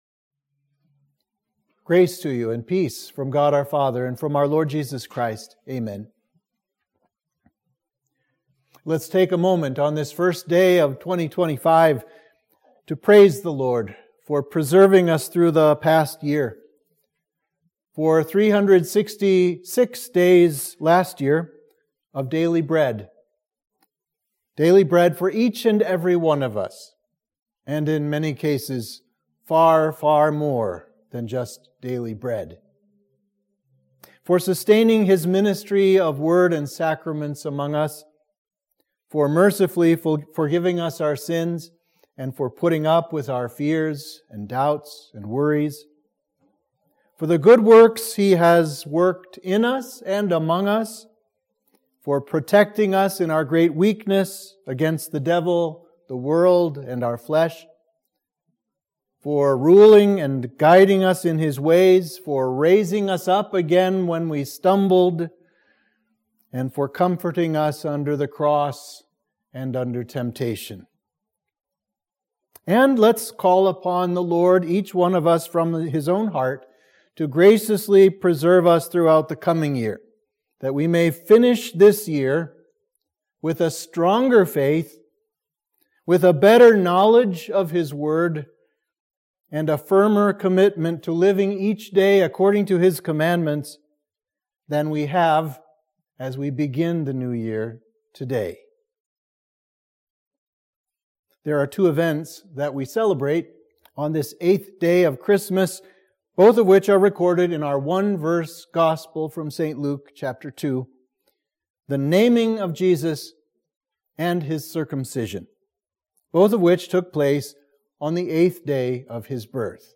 Sermon for the Circumcision & Naming of Our Lord